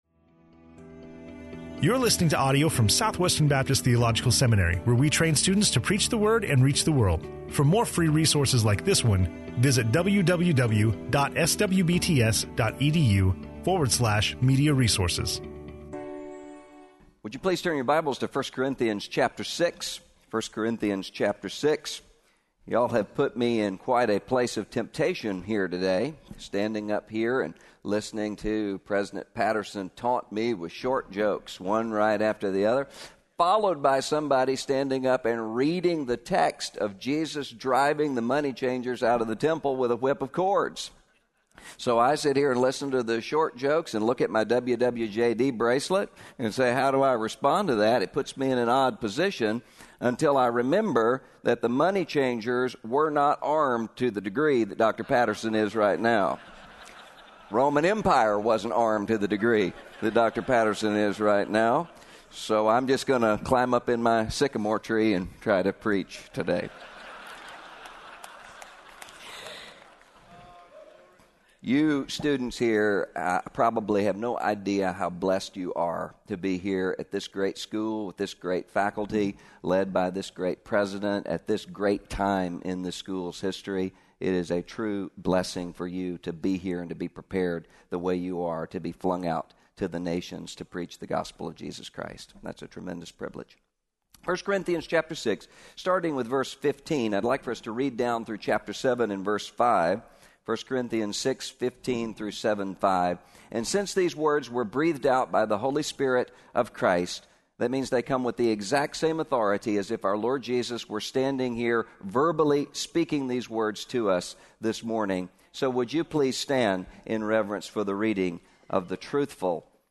Dr. Russell Moore speaking on I Corinthians 6:15-7:5 in SWBTS Chapel on Thursday September 5, 2013
SWBTS Chapel Sermons